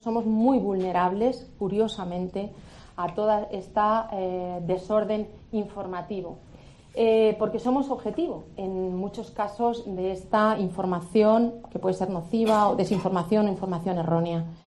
Este miércoles, durante una charla sobre cómo impacta Internet en el sector legal, Delgado confesó que ha sido "víctima" de manipulación informativa "por ser mujer", algo que "hace mucho daño y es muy difícil quitarse de encima".